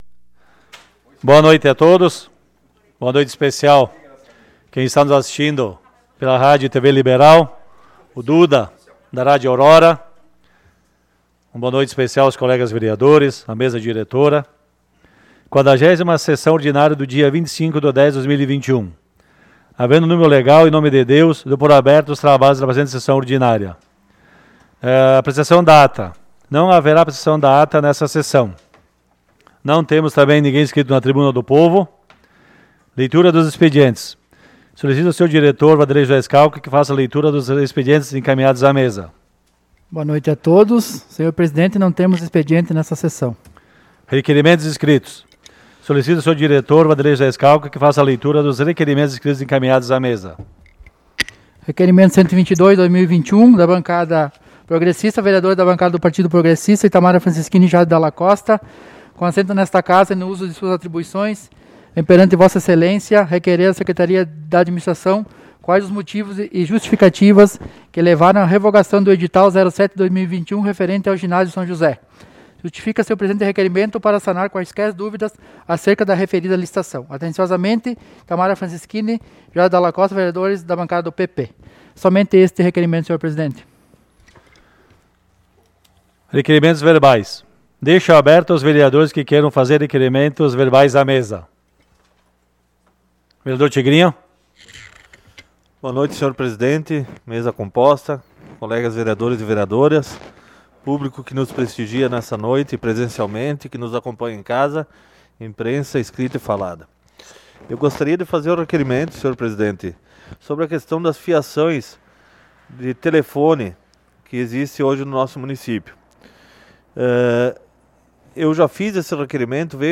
Sessão Ordinária do dia 25 de Outubro de 2021 - Sessão 40